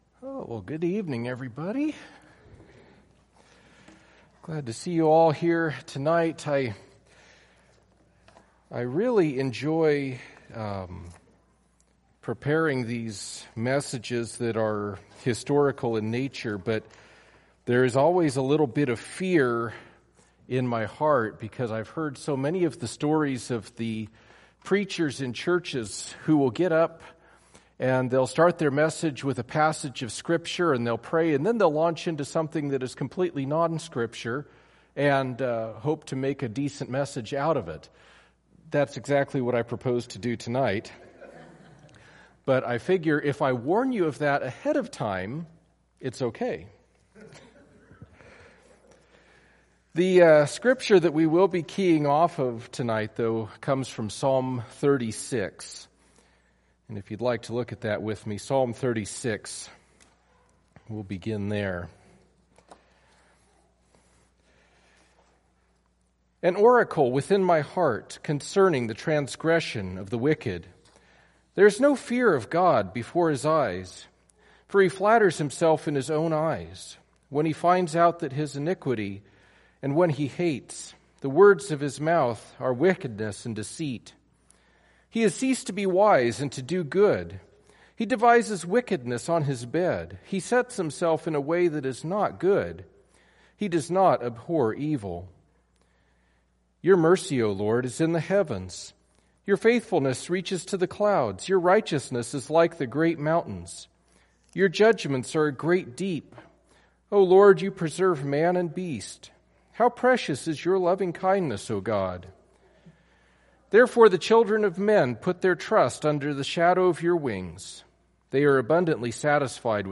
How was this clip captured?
Psalm 36 Service Type: Wednesday Evening Topics